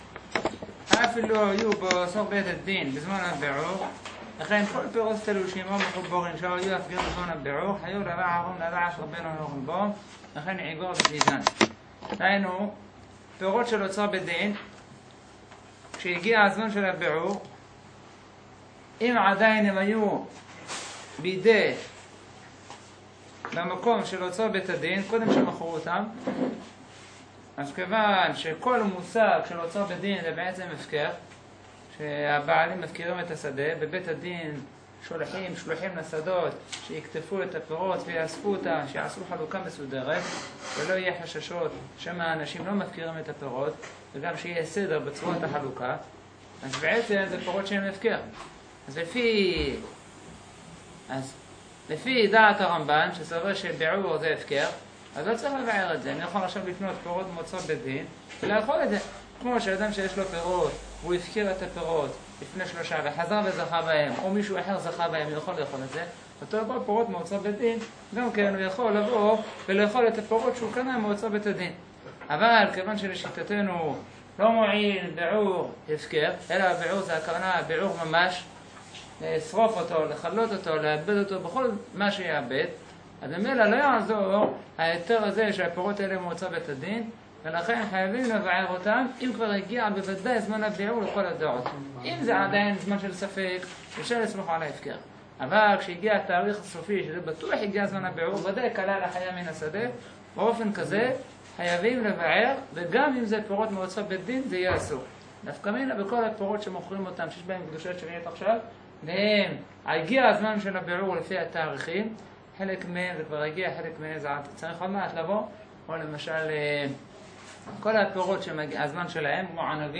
שיעור בספר "שנת השבע"